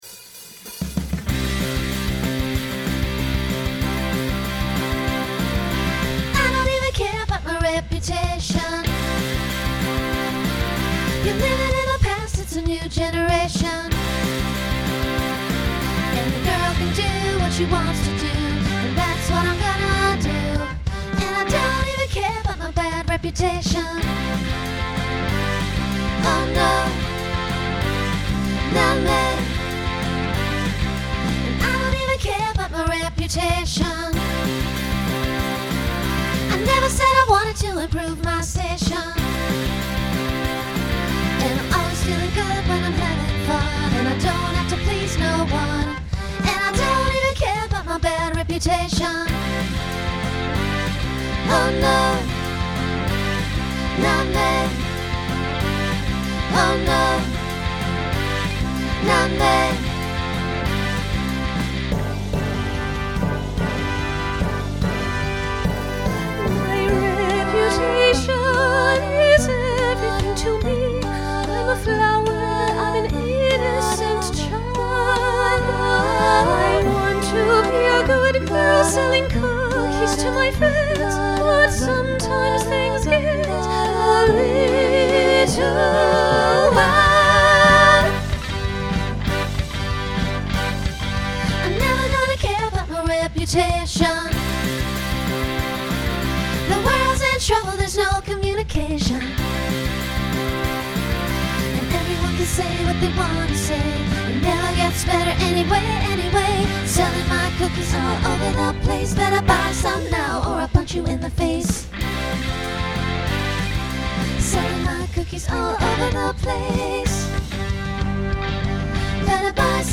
Genre Rock
Story/Theme Voicing SSA